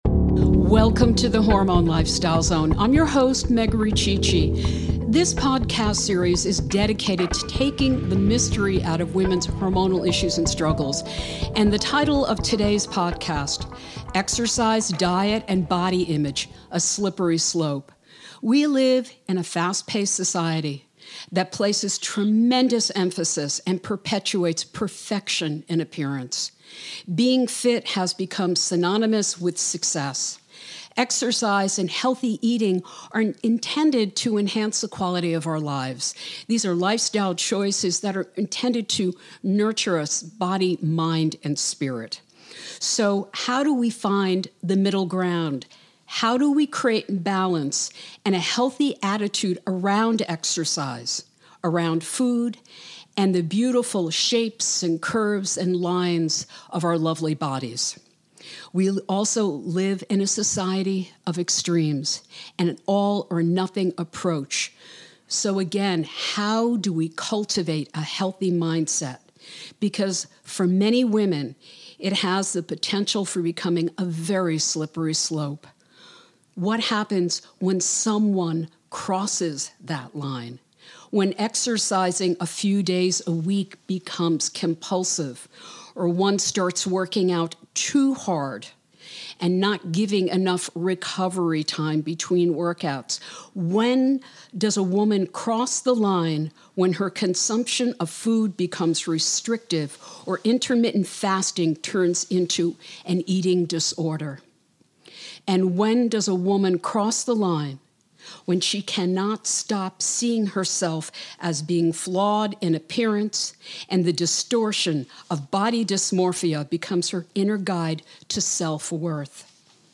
Join me on this episode as I explore these questions and much more with two super knowledgable pro's that understand the in's and out's of women's fitness!